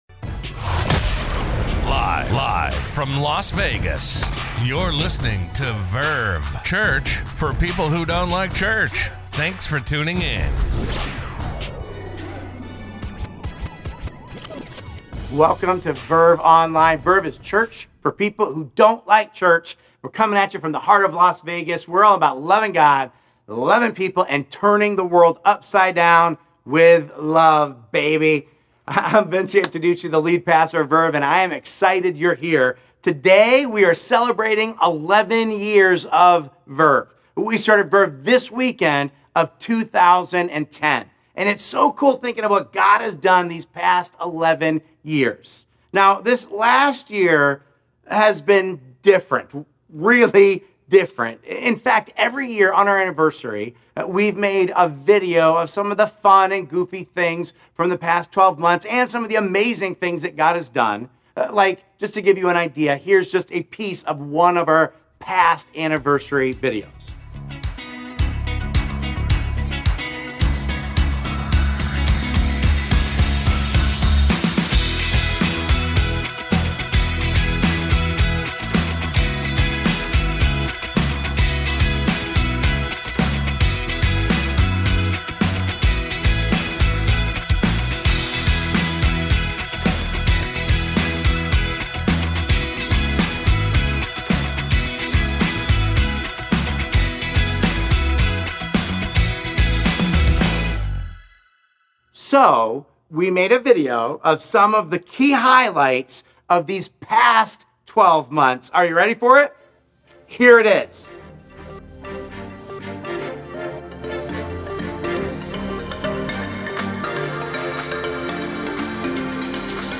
Message: "The Bible?"